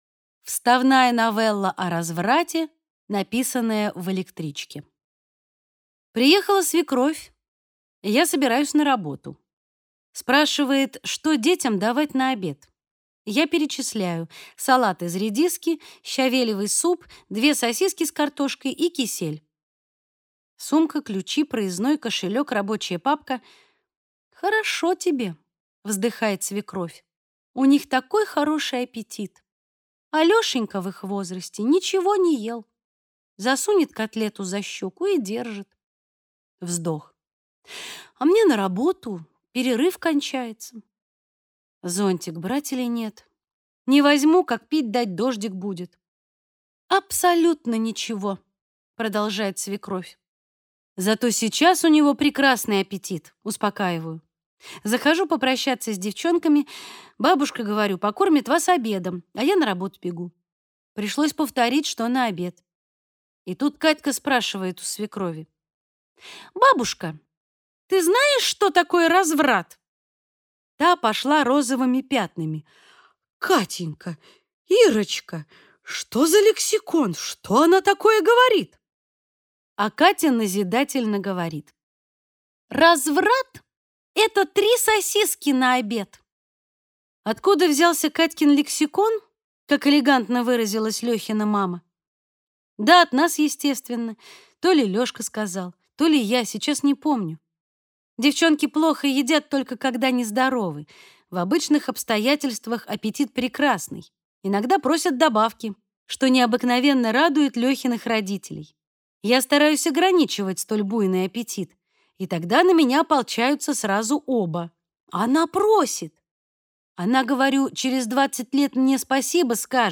Аудиокнига Детский альбом.